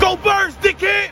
go birds dickhead Meme Sound Effect
This sound is perfect for adding humor, surprise, or dramatic timing to your content.